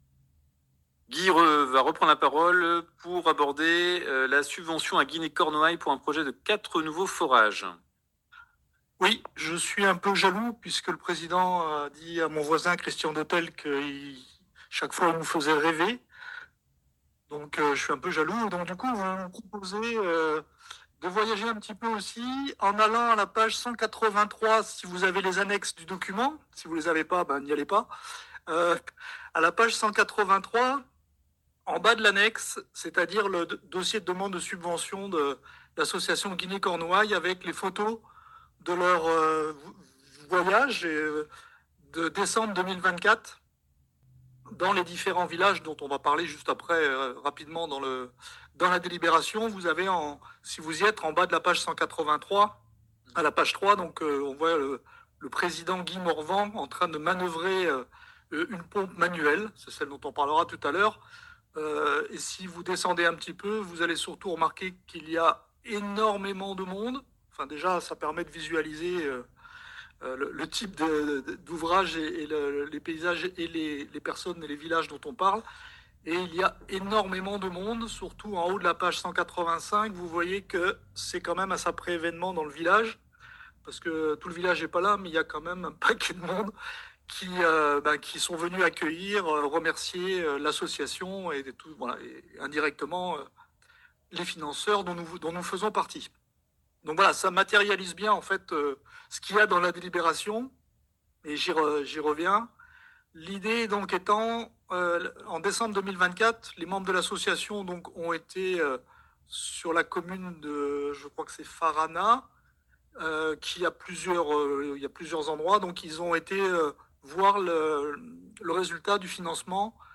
Guy Pagnard, maire de St Yvi et président de la commission Eau a défendu avec enthousiasme la demande Guinée-Cornouaille.